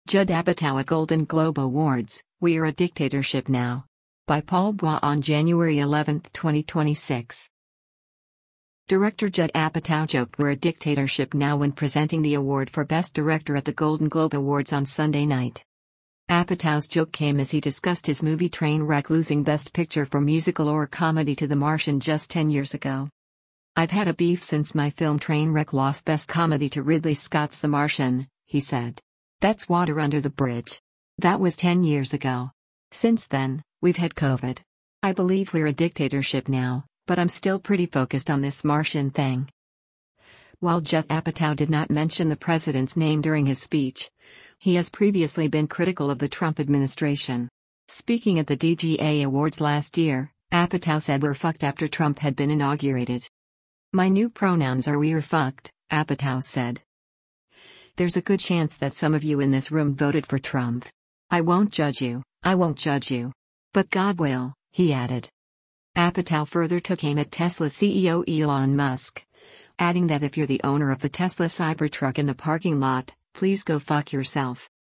Director Judd Apatow joked “we’re a dictatorship now” when presenting the award for Best Director at the Golden Globe Awards on Sunday night.